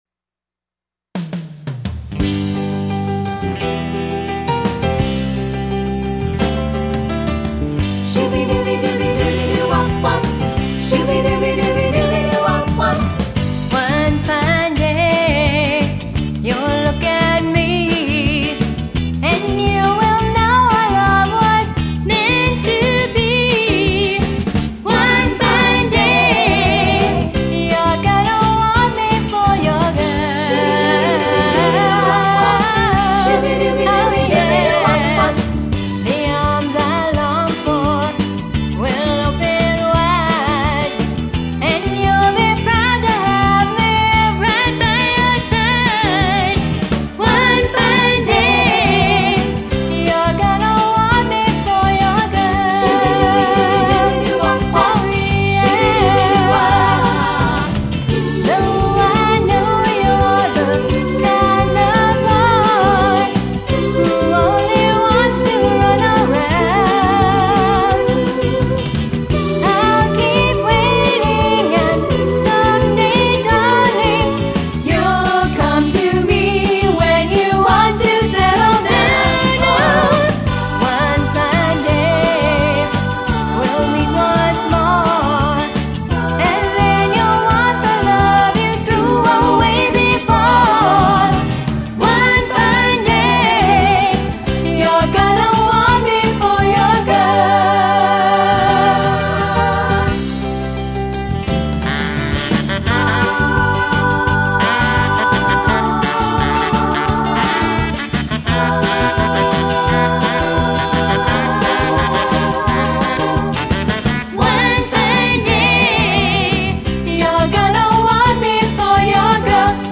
They have a choreographed show with harmonies and solos.